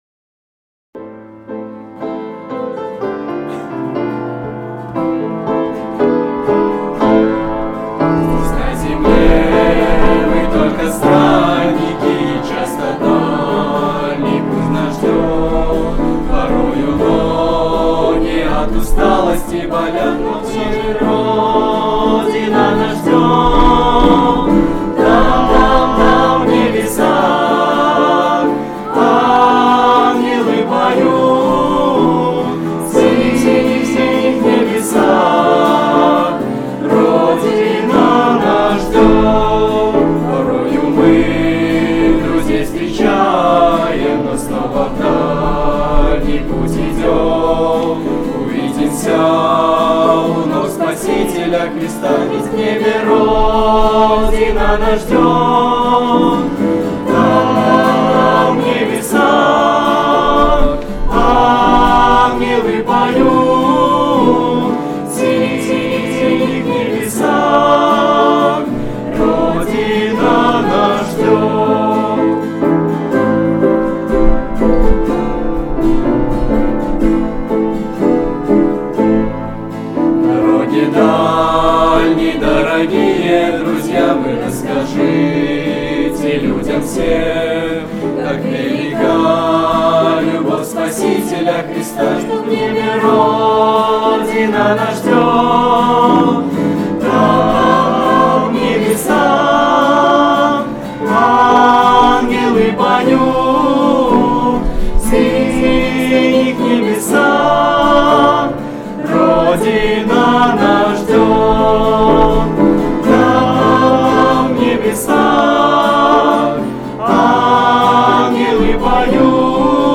11-09-16 / Здесь на земле мы только странники (Молодёжное прославление)